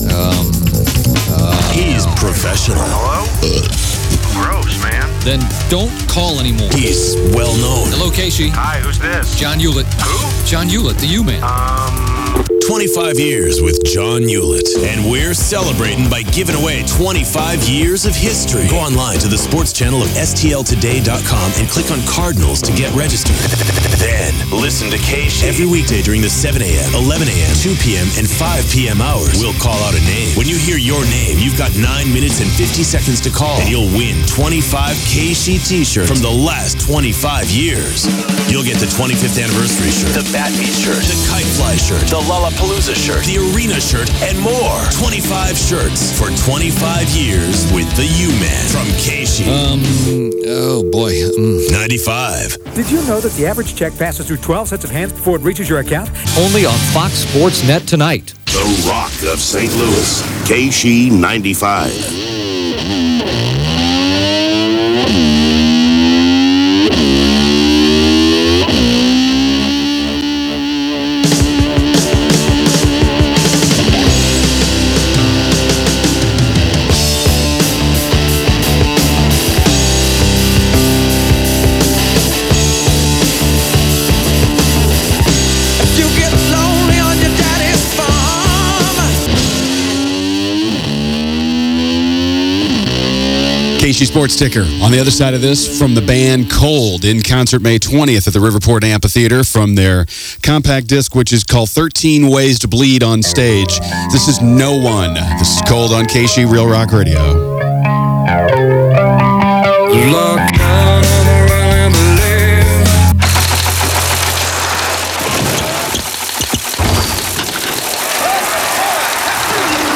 Aircheck